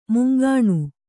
♪ mungāṇu